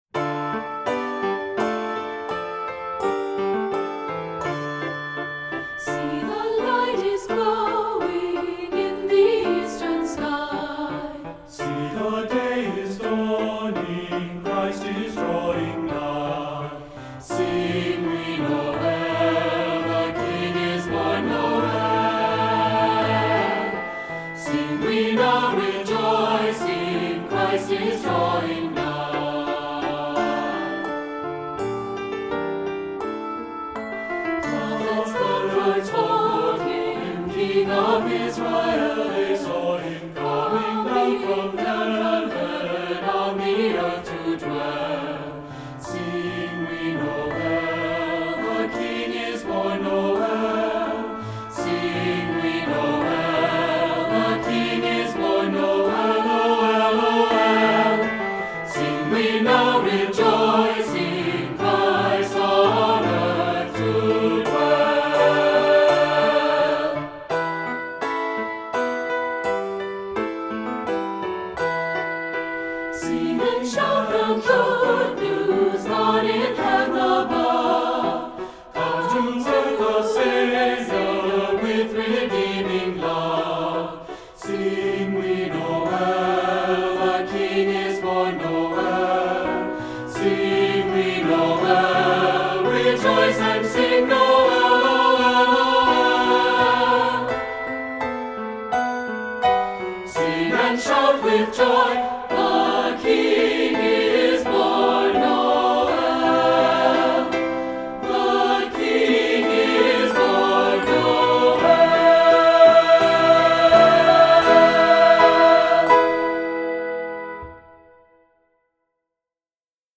Voicing: 2-part or SATB